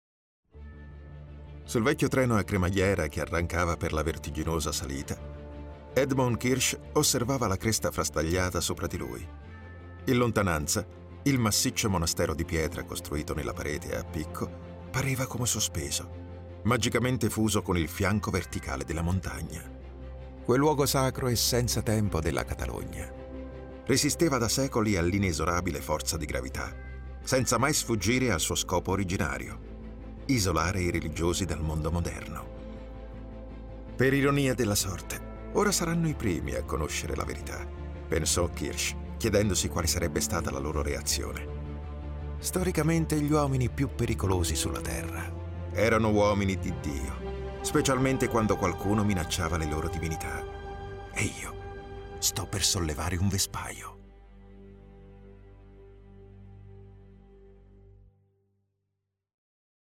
Male
Versatile, Corporate, Deep, Warm
Audio equipment: The recordings are made in my home studio equipped with soundproof booth, Neumann tlm 103 microphone, Apollo MKII SOUND CARD which guarantees white quality